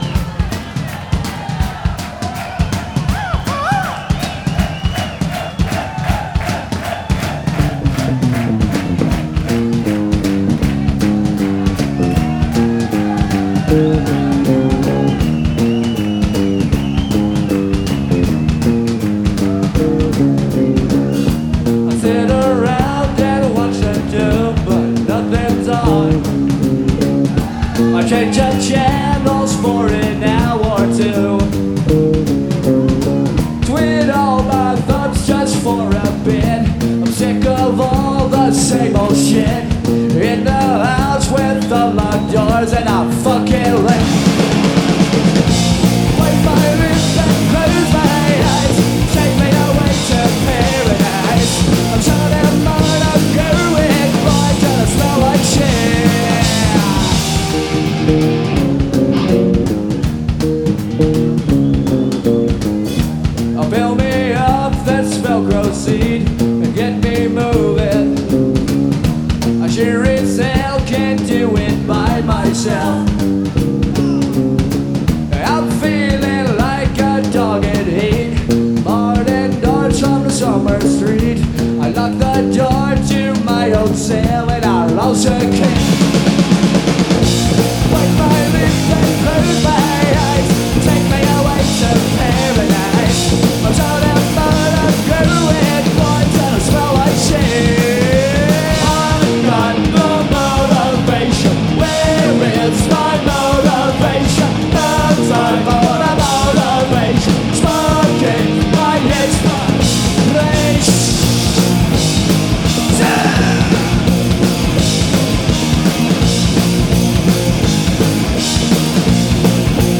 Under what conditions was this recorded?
Live at Garatge Club, Barcelona 1994